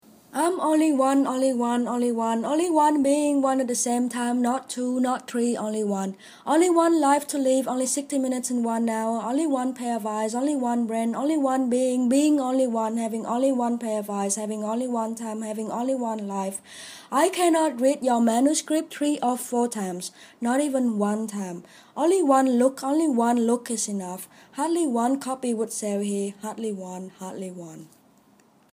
Khi còn chưa nổi tiếng, bà cũng đã bị chế giễu khá nhiều, đến mức một nhà xuất bản đã gửi bà một bức thư từ chối nhại lại chính cách hành văn của bà. Cái thư này cũng thông minh và buồn cười đến mức tôi đã bắt chước kiểu đọc thơ của Gertrude Stein để đọc lá thư này.